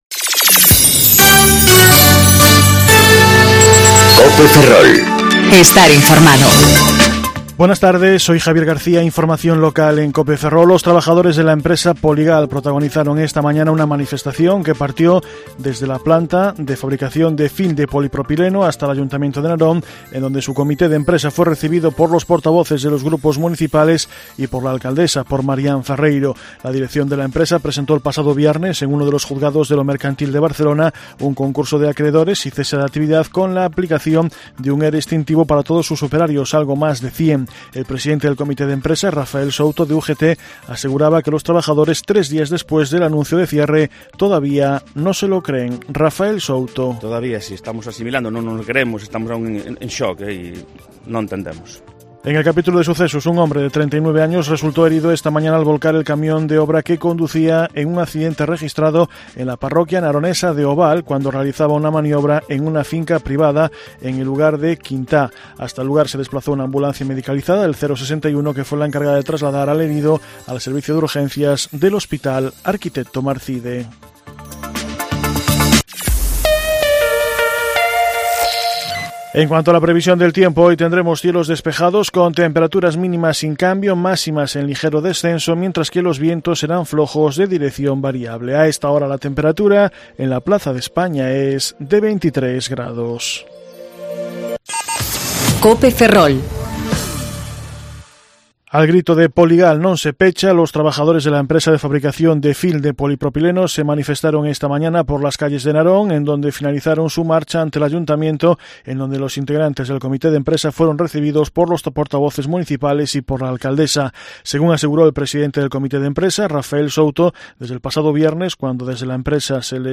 Informativo Mediodía Cope Ferrol - 25/02/2019 (De 14.20 a 14.30 horas)